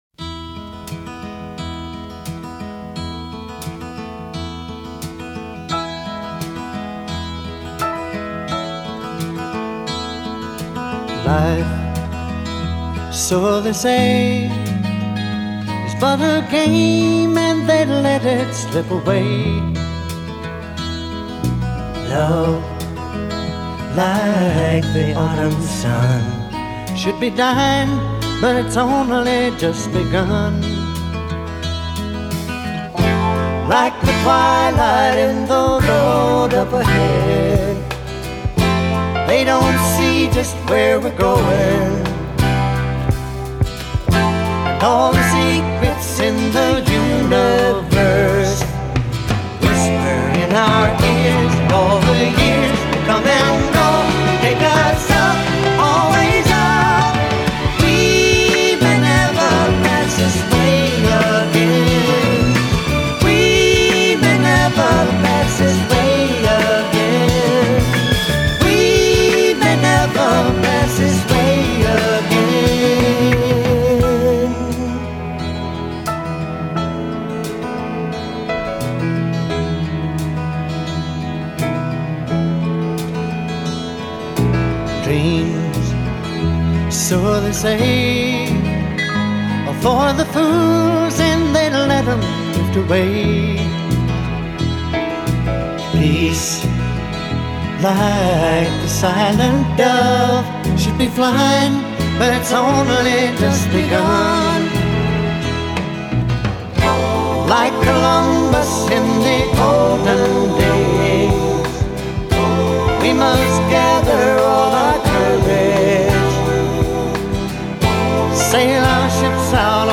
Tag: Soft rock